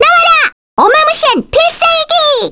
May-Vox_Skill3_kr.wav